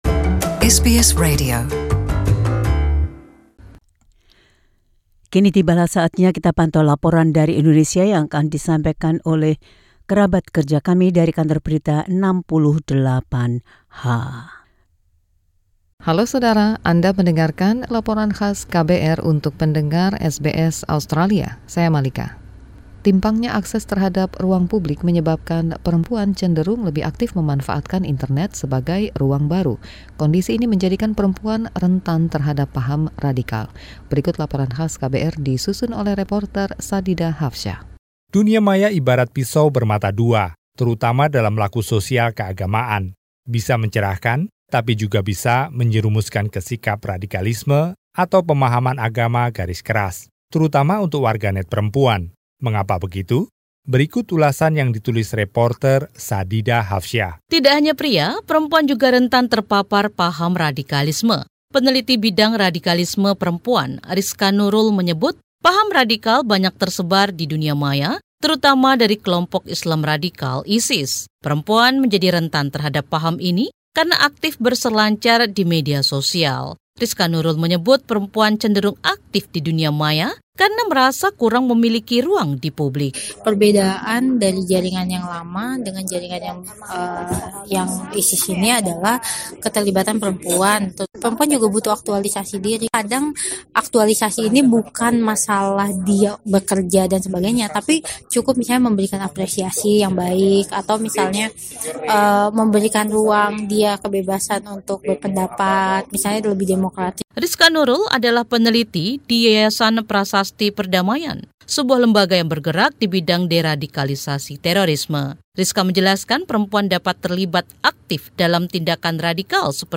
This report from the team at KBR 68H explains a worrying trend.